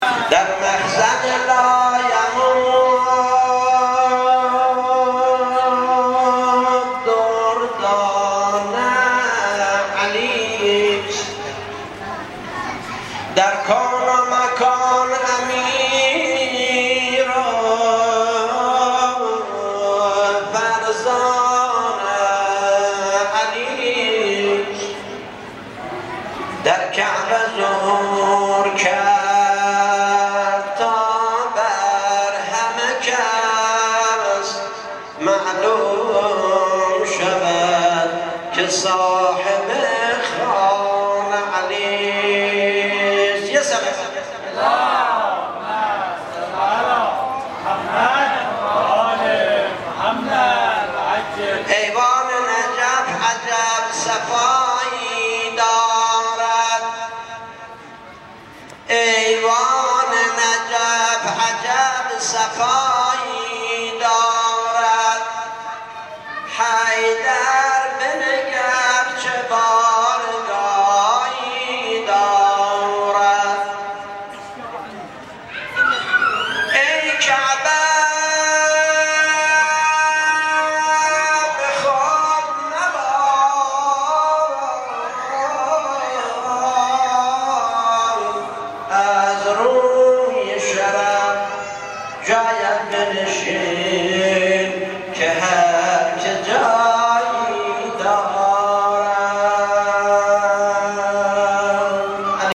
جمعه 12 شهریور 1395 در شب ازدواج حضرت امیرمؤمنان علی بن ابی طالب علیه السلام و سرور زنان بهشتی حضرت زهرا سلام الله علیها در مسجد جامع جشن و مدح خوانی اهلبیت علیهم السلام داشتیم.
مدح خوانی